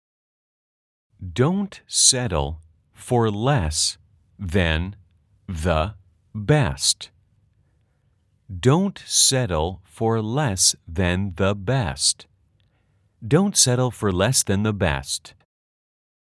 아주 천천히-천천히-빠르게 3번 반복됩니다.
/ 도온 쎄를 퍼 / 레에스 댄더/ 베에스트 /